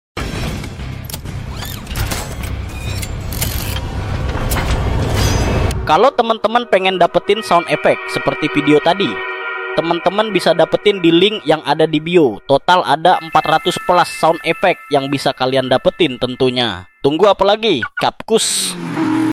✨ Isi Bundle: Lebih dari 450 efek suara premium berkualitas tinggi (WAV/MP3) Termasuk: Cinematic Boom, Whoosh, Hit, Ambience, Sci-Fi, Horror, Nature, Explosions, dan banyak lagi!